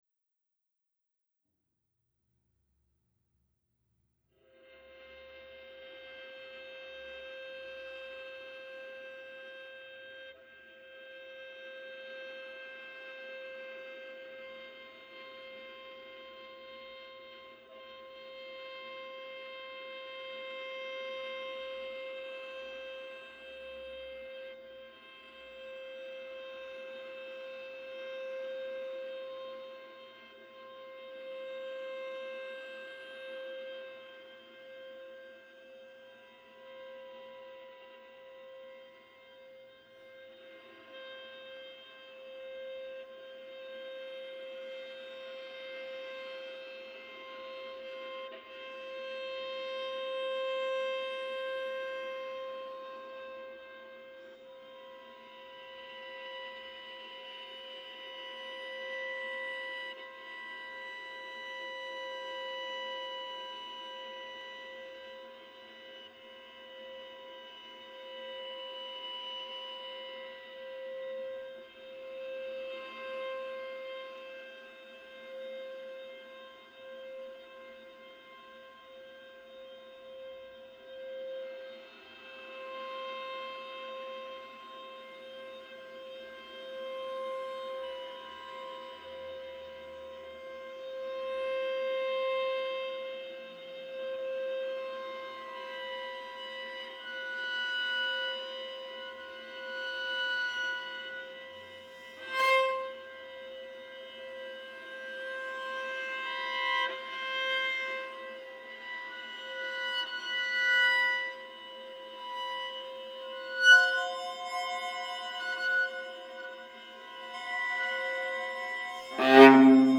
viola Documentation